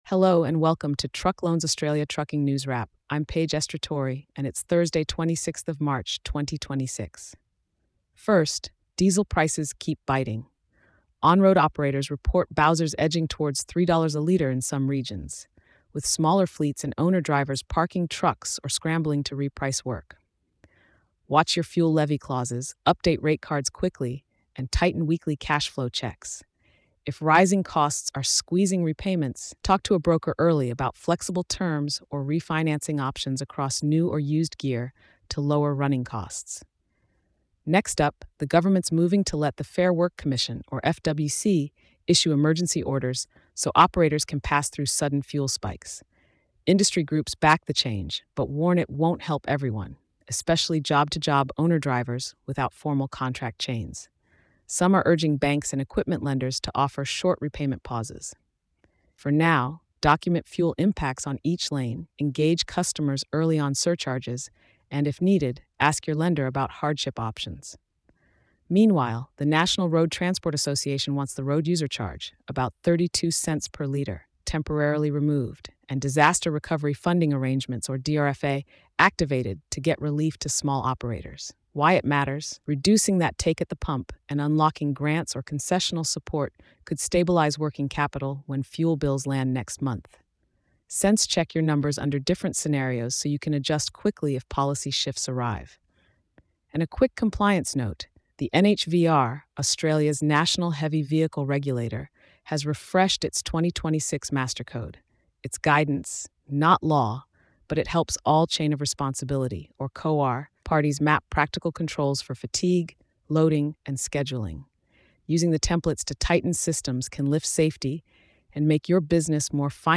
Designed for busy owners and drivers, it’s your no-fuss briefing to stay informed, plan ahead, and keep wheels turning—with context from reputable industry sources and an even, practical tone.